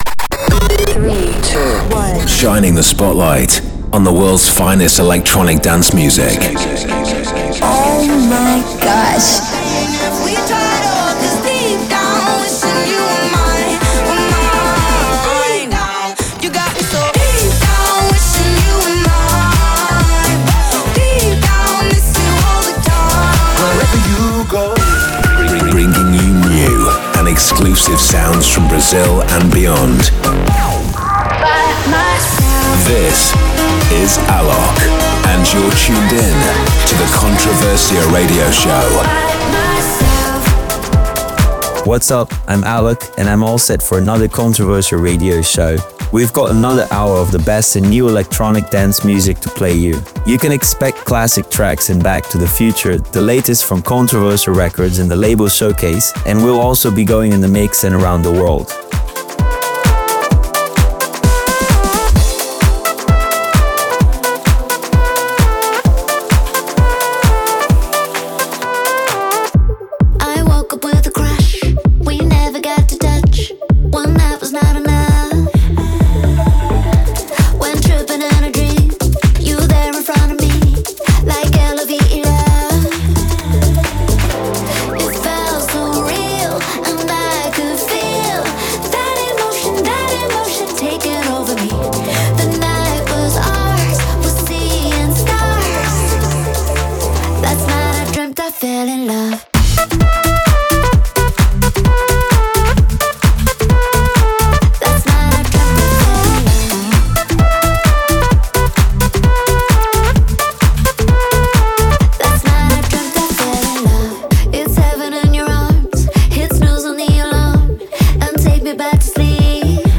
electronic music